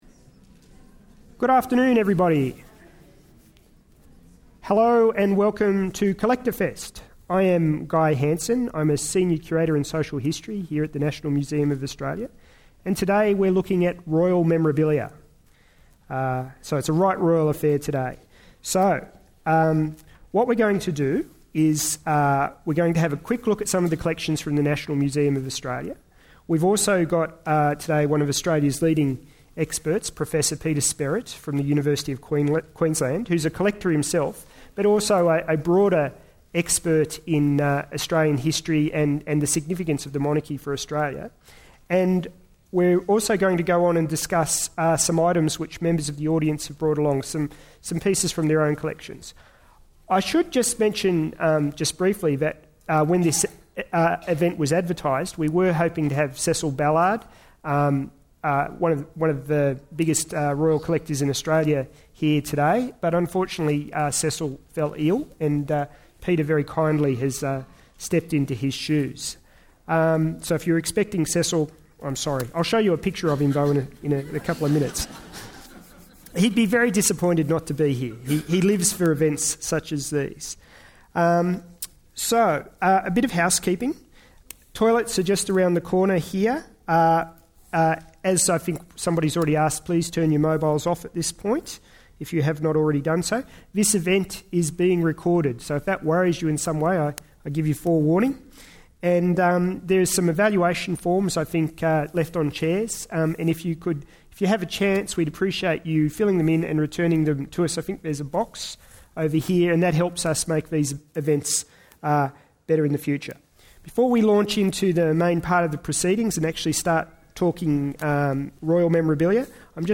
then showcase five items belonging to collectors from the audience